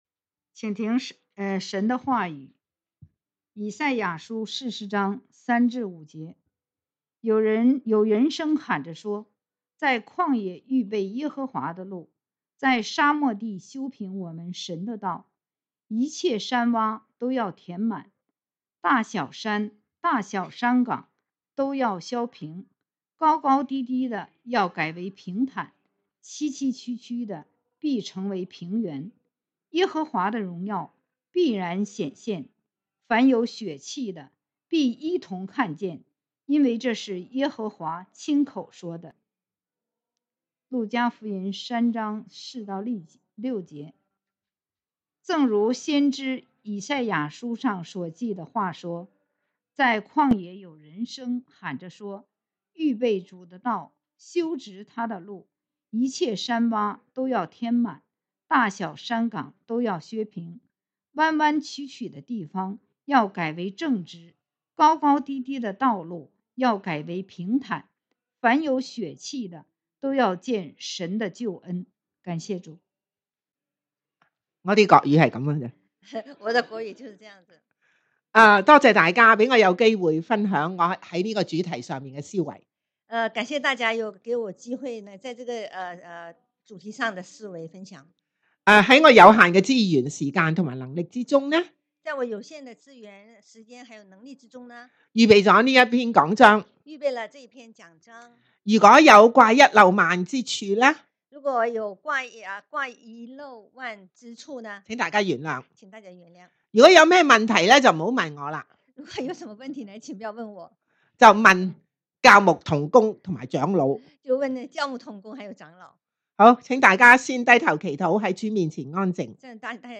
Sermons | 基督教主恩堂